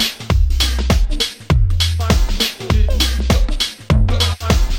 描述：有人声的打击乐。
Tag: 100 bpm Ethnic Loops Groove Loops 830.92 KB wav Key : A